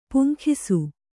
♪ puŋkhisu